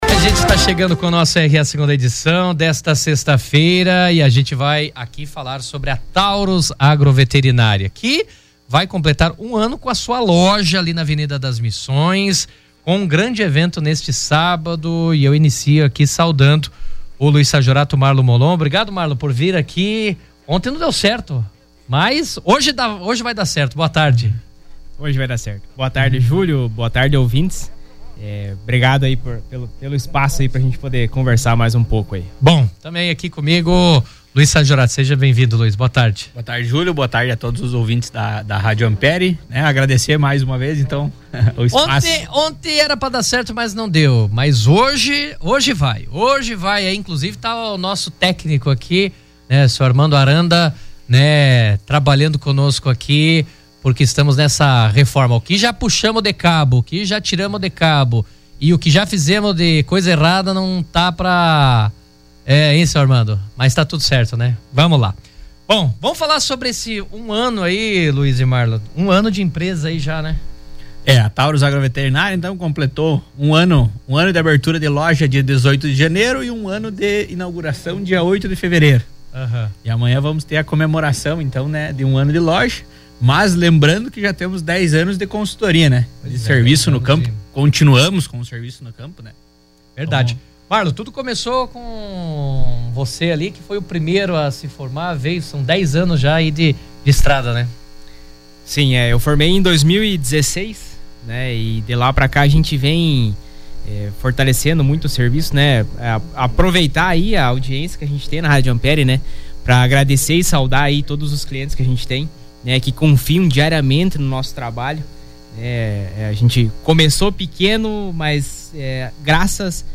Durante a entrevista, eles convidaram a comunidade e produtores rurais para um evento especial que acontece neste sábado, com formato de feira de negócios e a participação de diversos parceiros e fornecedores.